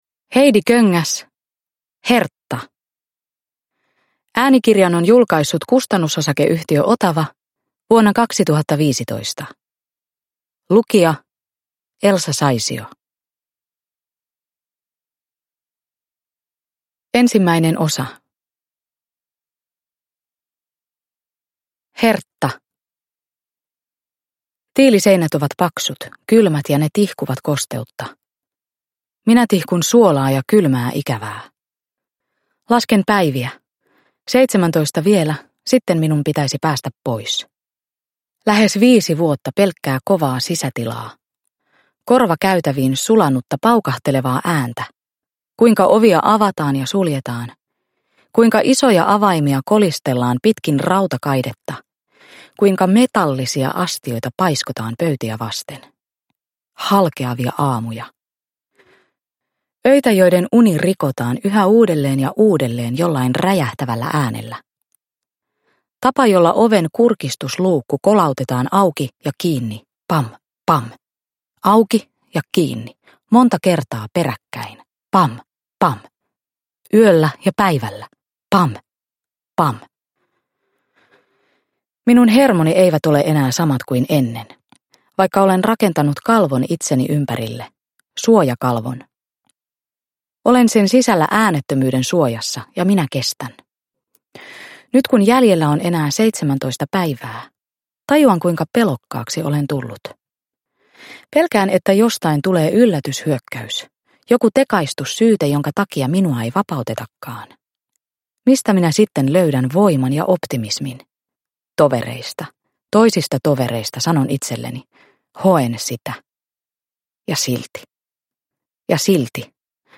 Hertta – Ljudbok – Laddas ner